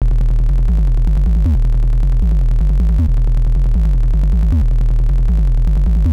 • quick muddy bass techno 137 Em.wav
quick_muddy_bass_techno_137_Em_MZq.wav